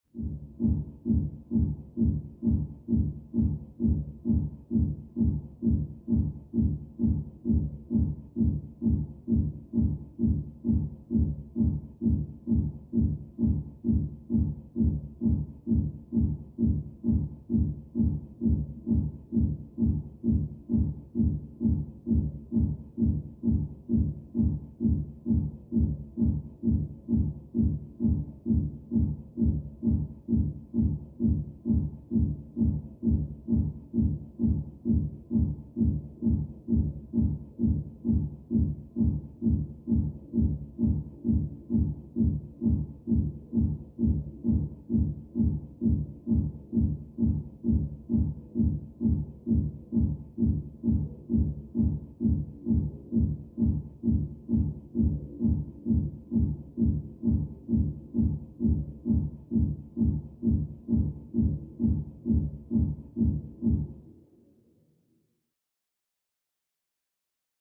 Music; Electronic Dance Beat, From Down Hallway.